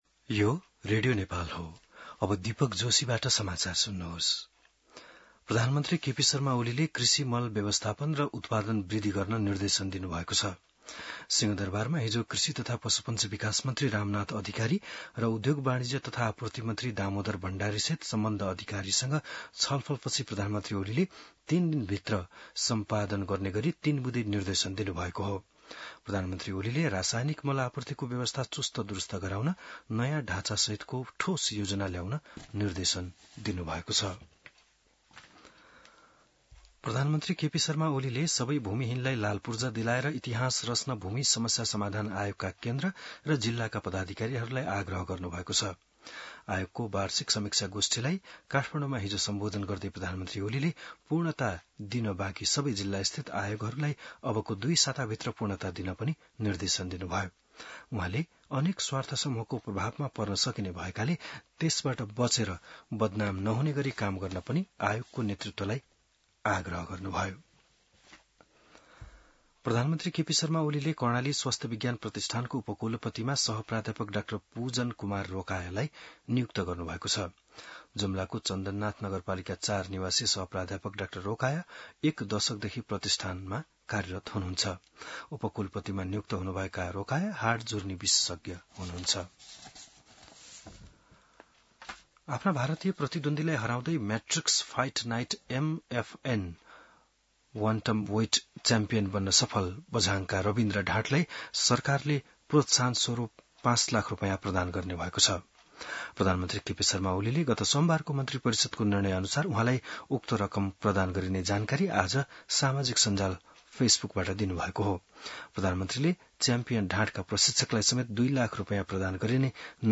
An online outlet of Nepal's national radio broadcaster
बिहान १० बजेको नेपाली समाचार : २८ साउन , २०८२